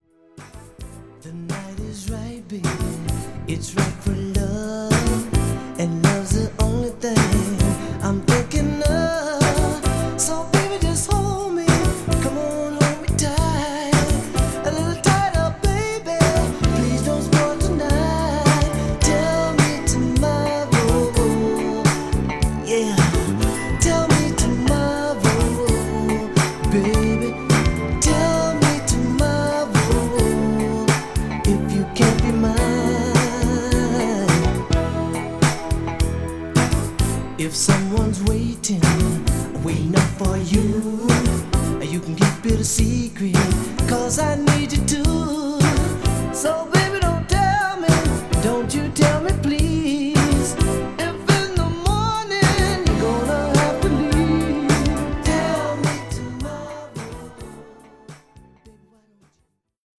セクシーなムードもほんのり感じさせるアーバンなミディアム・ソウル、ジャジーなアウトロもいい感じです。